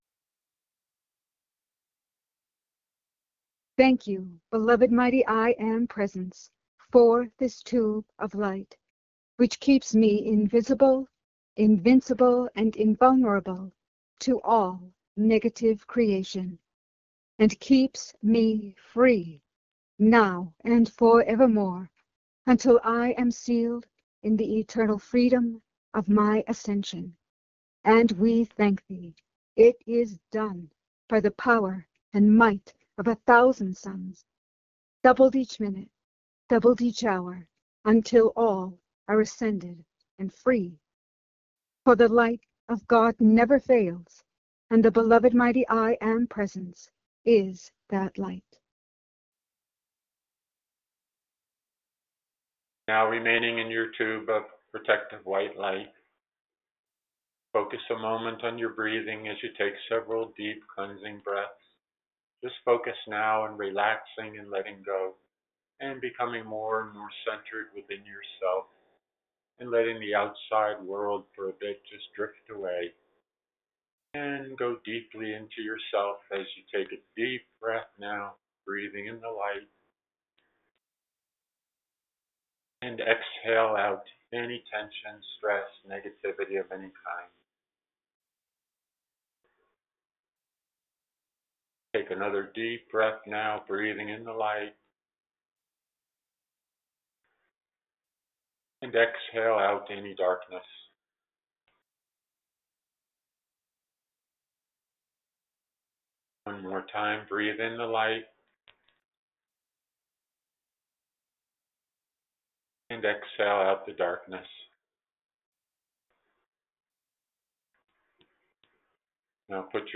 Join in and follow along in group meditation with Lord Sananda (Jesus).